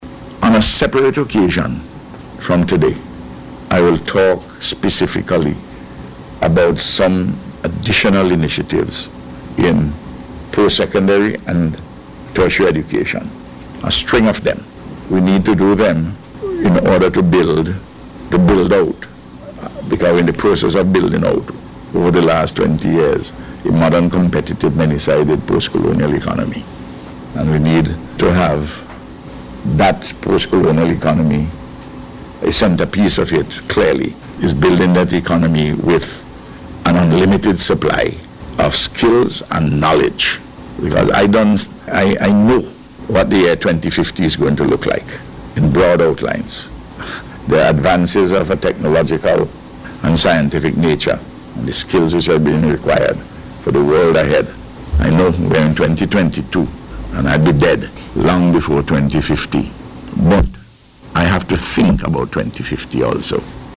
At a Media Conference yesterday, the Prime Minister said he will shed the Foreign Affairs and Foreign Trade portfolio, and promote Senator Keisal Peters as Minister of Foreign Affairs and Foreign Trade.